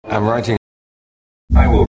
Acoustically, the stormtrooper’s speech is filtered, by a physical helmet or by digital tinkering, or by both.
Further, there’s an accent discrepancy between the Englishman Craig and the American-sounding stormtrooper; in the forensic situation, this would be called ‘accent disguise’.